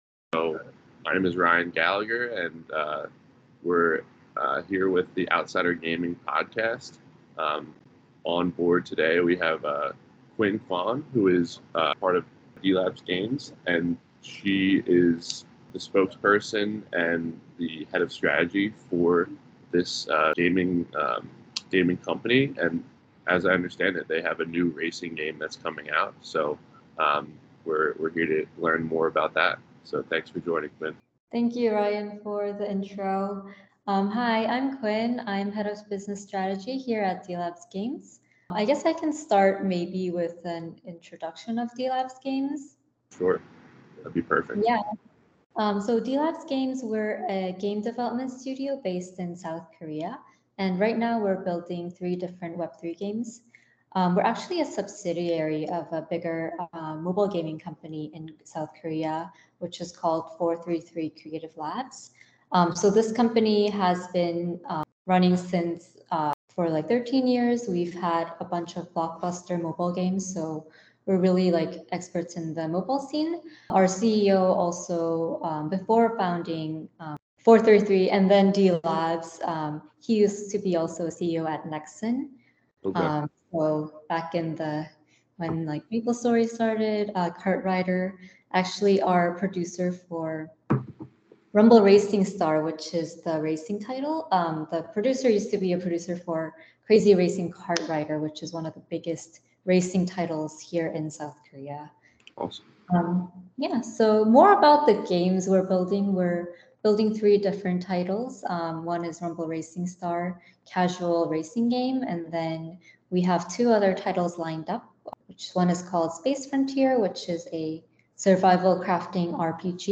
Rumble Racing Star, their crowning achievement, is a casual racing game that just finished a closed beta to widespread acclaim. This interview is a rare chance to learn about the game and the developer’s ambitions before the open beta in October.